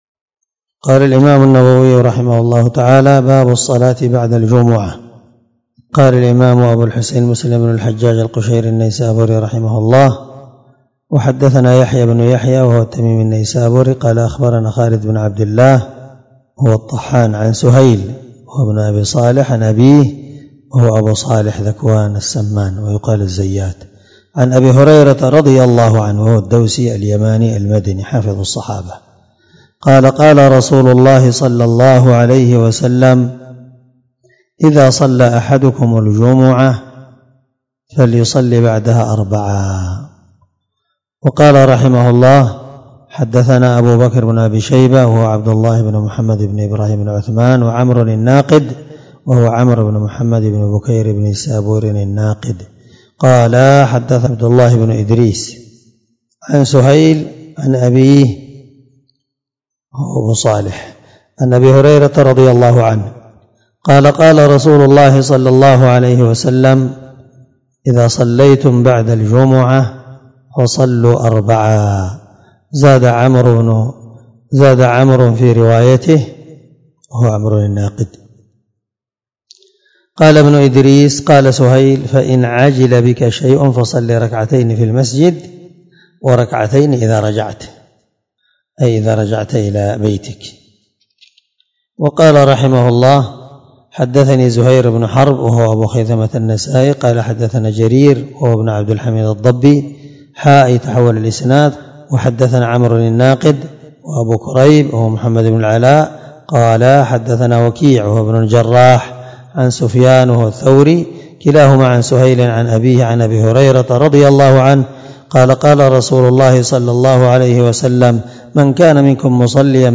دار الحديث- المَحاوِلة- الصبيحة